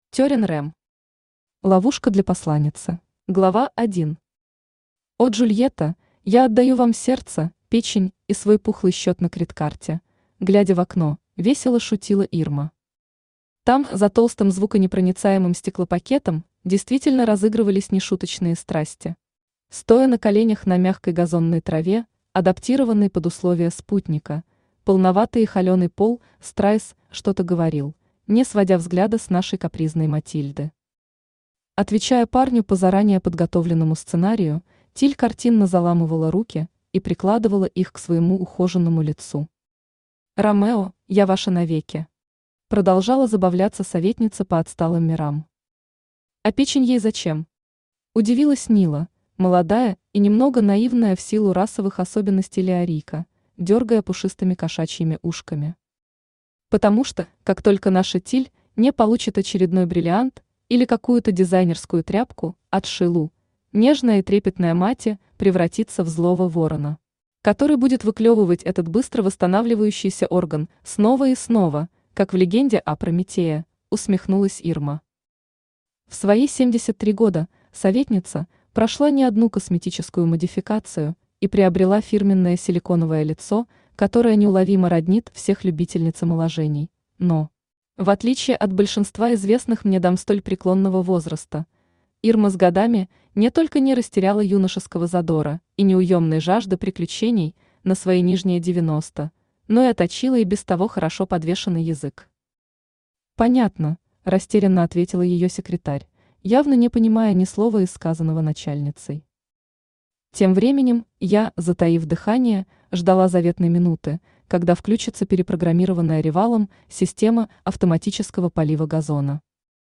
Аудиокнига Ловушка для посланницы | Библиотека аудиокниг
Aудиокнига Ловушка для посланницы Автор Терин Рем Читает аудиокнигу Авточтец ЛитРес.